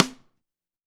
ST DRYRIM2.wav